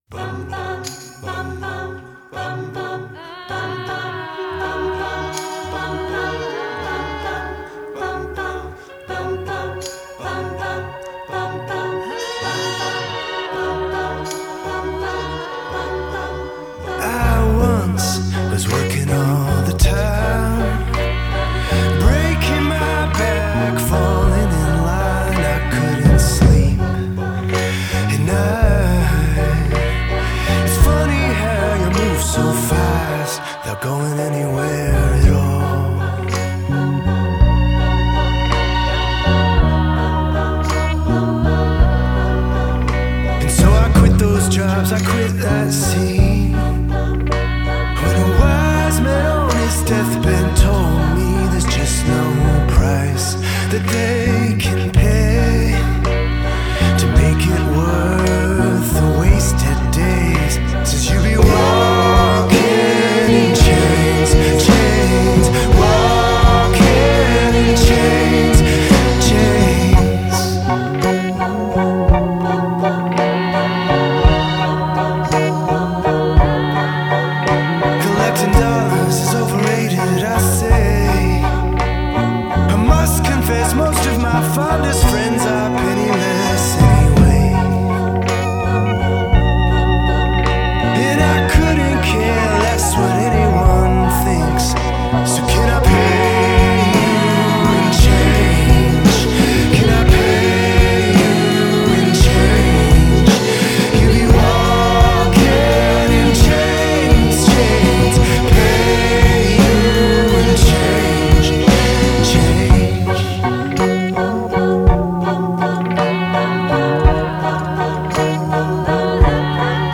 1960’s pop